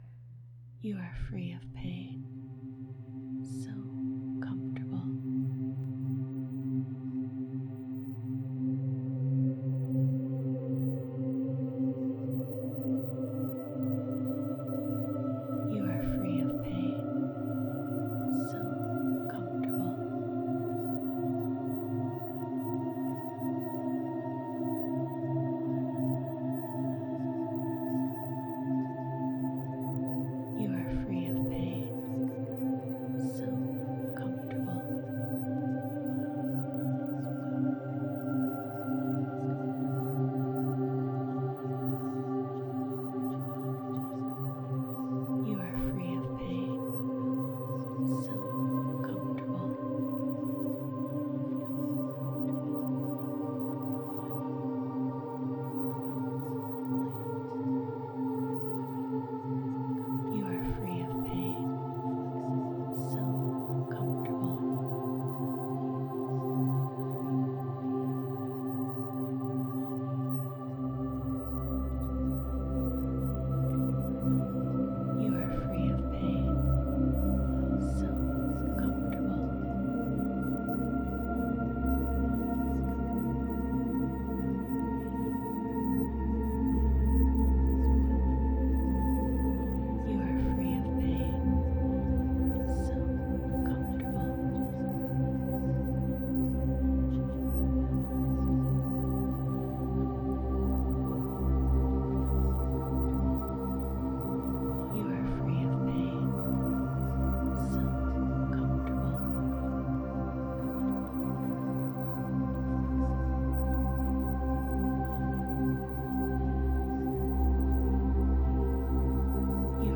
My apologies, I deleted the other post when I realized I made an error in the file by leaving some forced subliminals too unmasked
theta binaurals non exclusive meditation music there is a few affirmations that are meant to be heard most meant to be more inaudible (murmuring)
techniques for subliminal -forced subliminal -backward reverse masking -created by software -audio masking